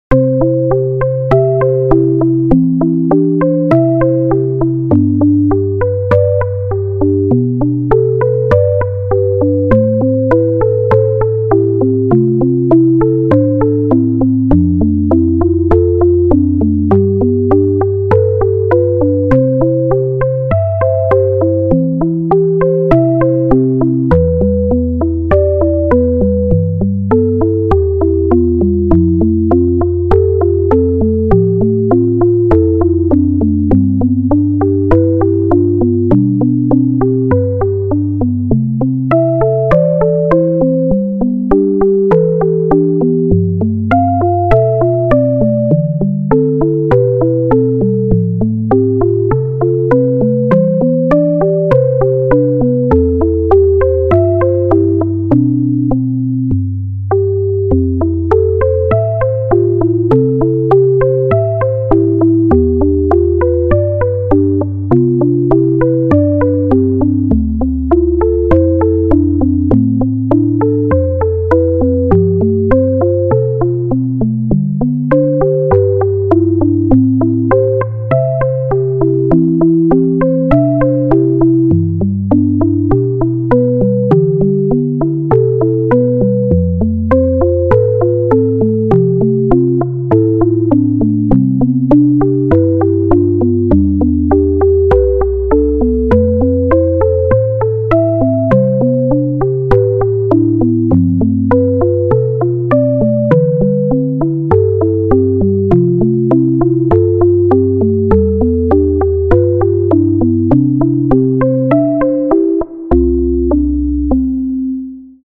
ループ対応。
BPM60